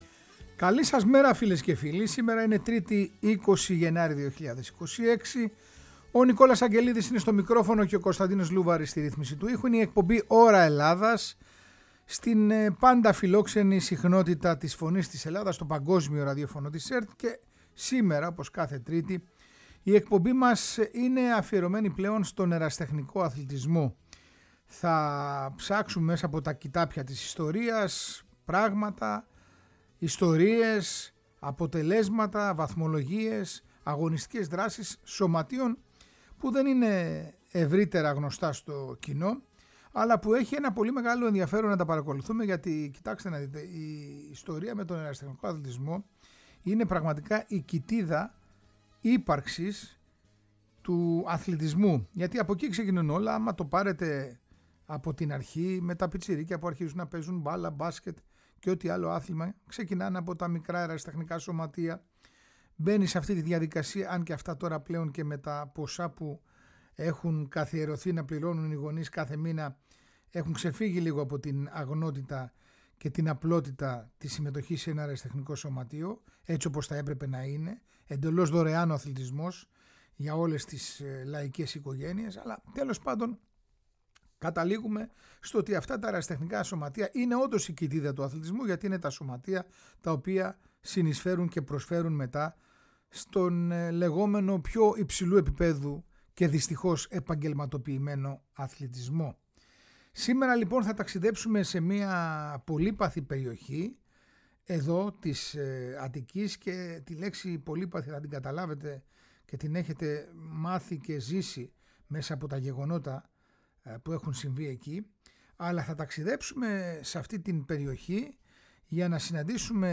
Μαθαίνουμε την ιστορία του Μανδραϊκού και ακούμε τον ύμνο της ομάδας που έχει φτάσει μέχρι την Γ’ Εθνική. Όπως κάθε Τρίτη η εκπομπή ΩΡΑ ΕΛΛΑΔΑΣ είναι αφιερωμένη στον Ερασιτεχνικό αθλητισμό.